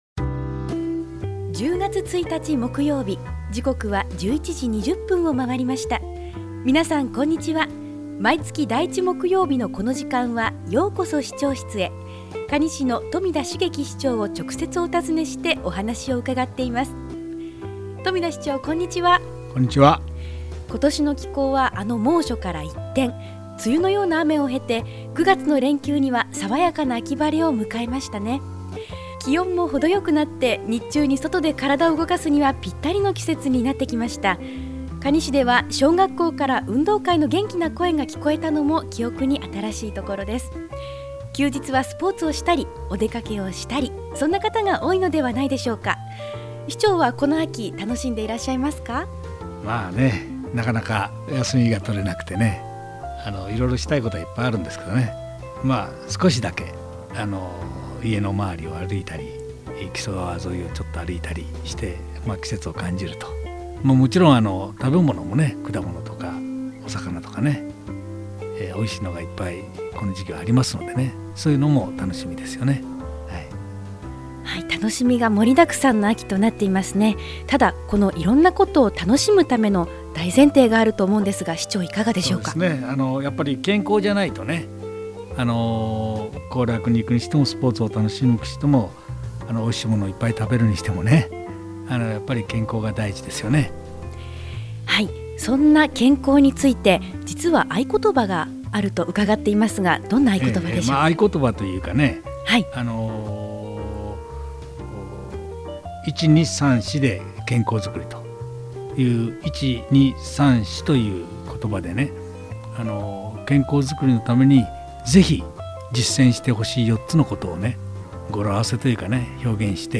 ようこそ市長室へ 2015-10-01 | ようこそ市長室へ 「ようこそ市長室へ」 可児市長室へ直接伺って、まちづくりの課題、魅力ある地域、市政情報などを 中心に、新鮮な情報を可児市長自ら、皆様にお届けする番組です。 ◎ 放送時間 毎月第１木曜日 １１：２０～ 可児市長 ： 冨田 成輝 ▼ 平成２７年１０月 １日 放送分 【今回のテーマ】 「１・２・３・４で健康づくり」 Podcast: Download « ようこそ市長室へ ようこそ市長室へ »